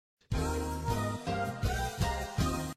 Well-be-right-back-Sound-Effect.mp3